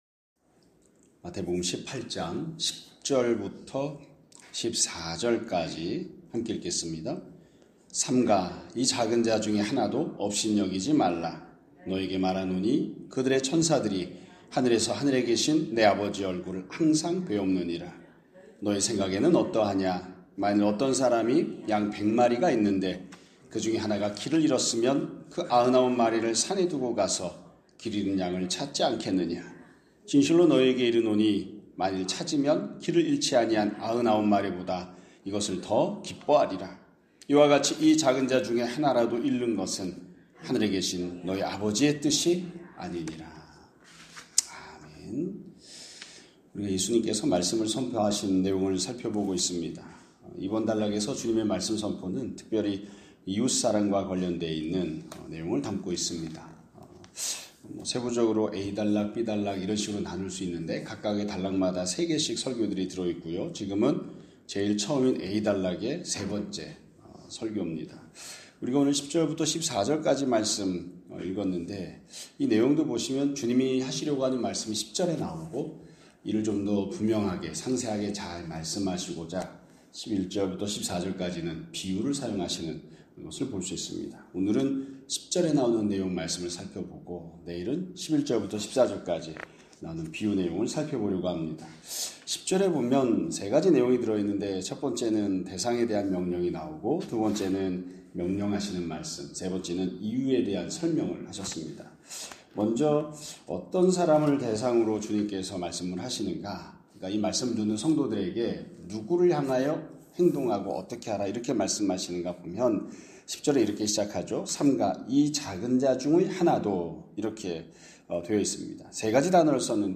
2025년 12월 8일 (월요일) <아침예배> 설교입니다.